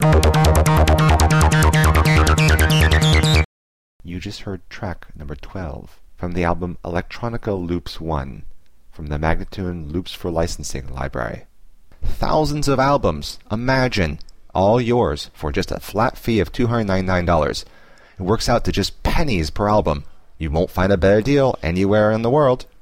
135-C-ambient:teknology-1032